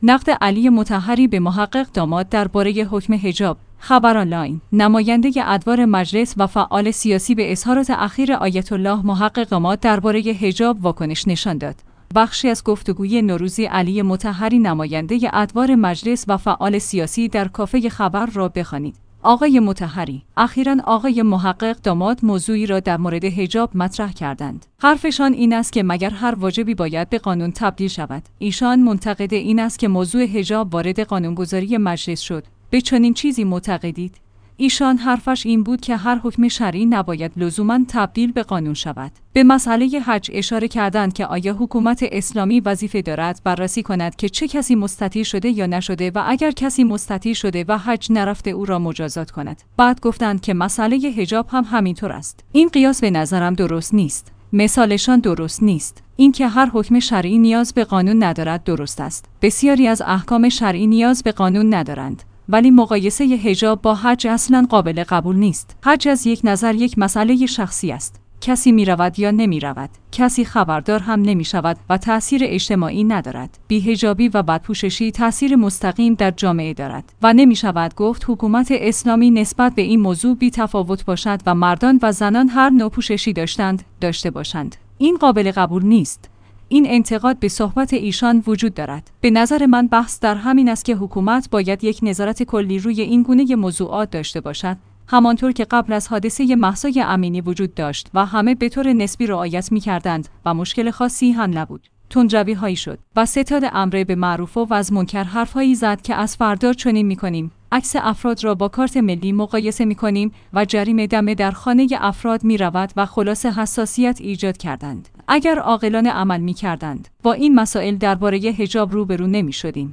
خبرآنلاین/ نماینده ادوار مجلس و فعال سیاسی به اظهارات اخیر آیت الله محقق داماد درباره حجاب واکنش نشان داد. بخشی از گفتگوی نوروزی علی مطهری نماینده ادوار مجلس و فعال سیاسی در «کافه خبر» را بخوانید؛ *آقای مطهری!